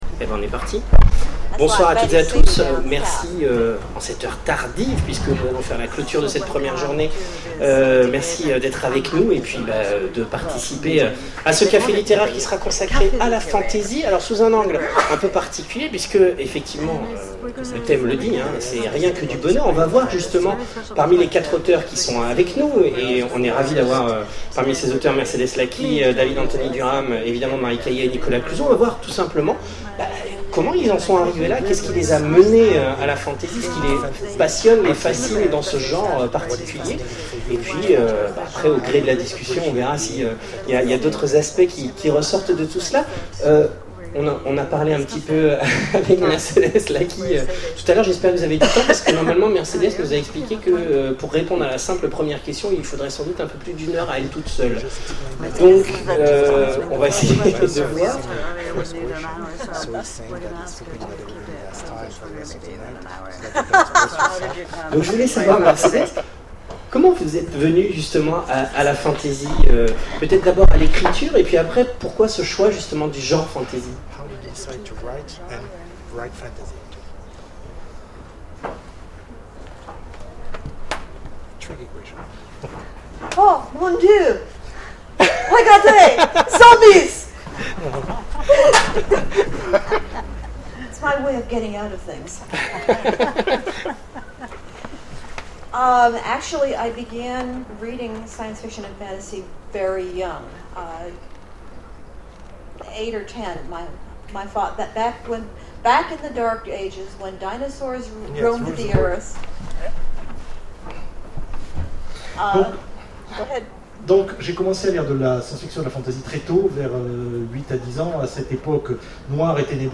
Imaginales 2012 : Conférence La fantasy, ce n'est que du bonheur !